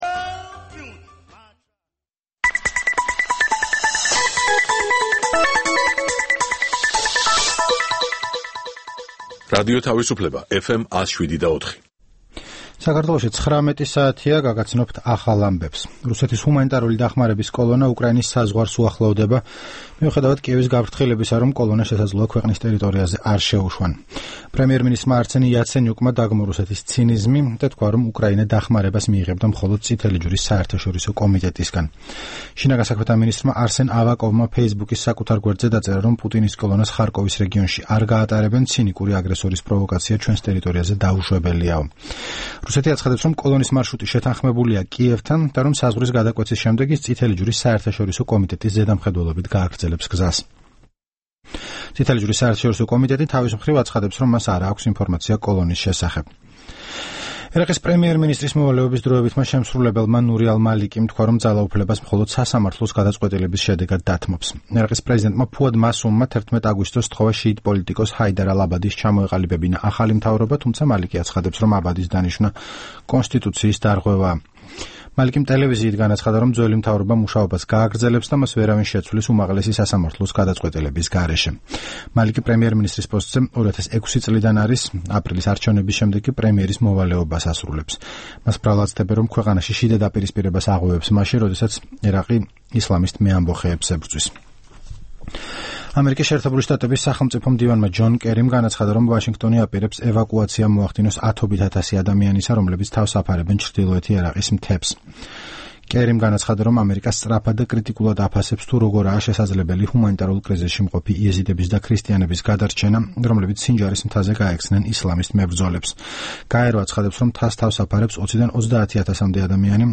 გავიხსენებთ, თუ რა ვითარებაში და როგორ დათმო საქართველომ ჯერ დიდი და პატარა ლიახვის, ფრონეს, კოდორის ხეობები, სულ ბოლოს კი, 2008 წლის 14 აგვისტოს, ახალგორის რაიონი. ჩვენს შეკითხვებს დღეს უპასუხებს გენერალი მამუკა ყურაშვილი, 2007-2008 წლებში საქართველოს შეიარაღებული ძალების სამშვიდობო ოპერაციების შტაბის ყოფილი უფროსი.